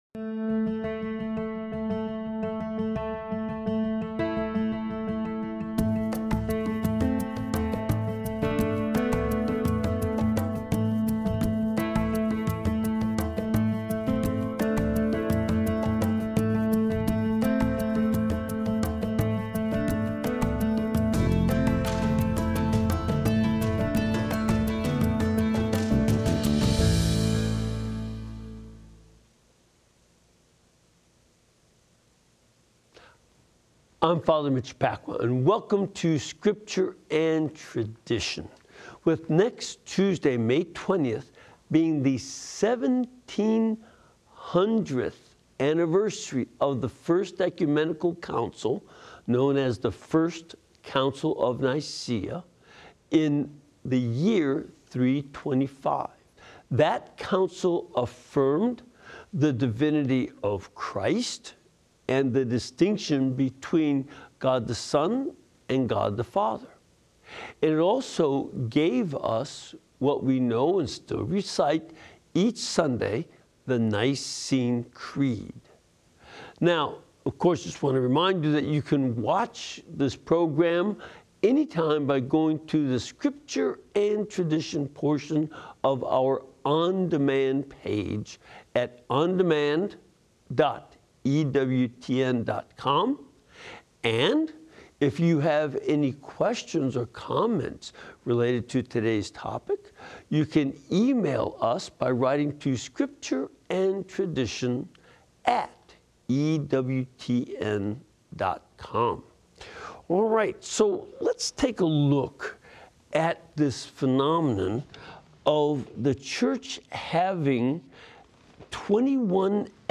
In his LIVE, interactive Bible Study, as we approach the seventeen hundredth anniversary of the First Ecumenical Council of Nicea, he discusses some of the issues and problems in the Church, which led the Council to affirm the divinity of Christ and give us what we know and still recite each Sunday, the Nicene Creed.